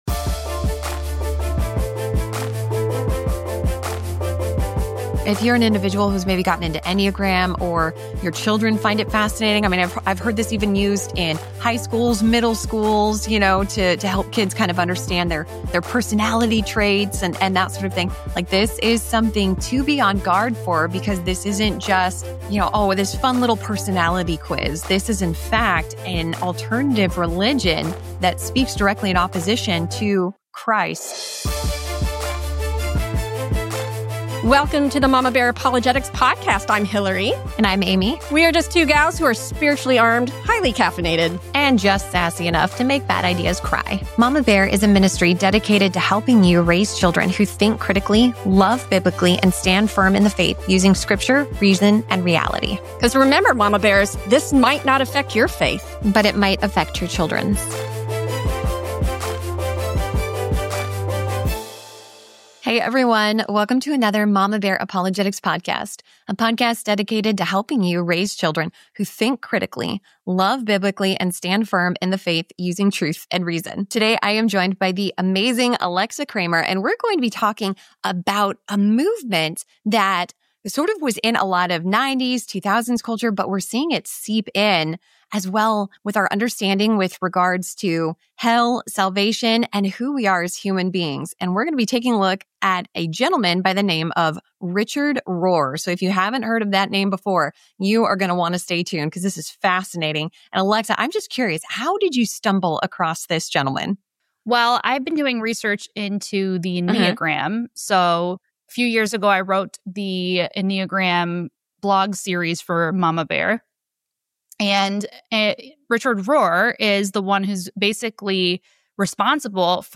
Please note that we had a connection error during this podcast that was not edited out of this.